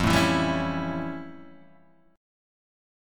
F+M9 chord